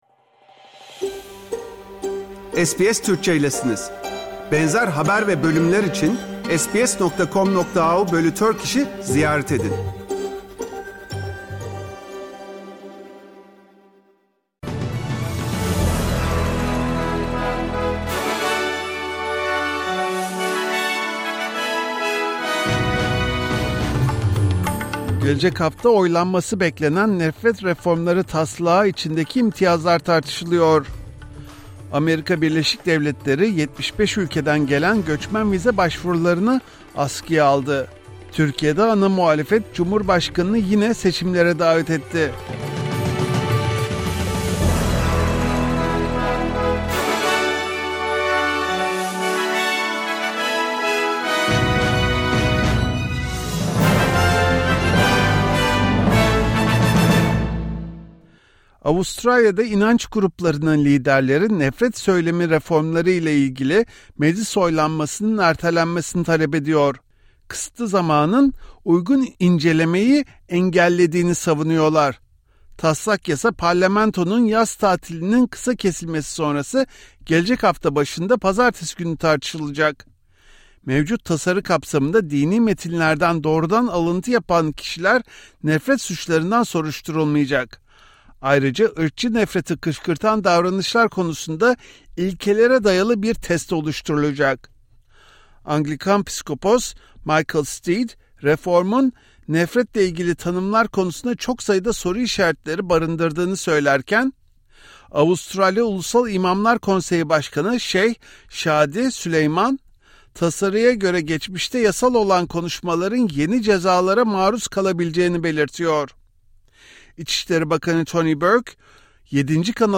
SBS Türkçe Haberler | 15 Ocak 2025 Perşembe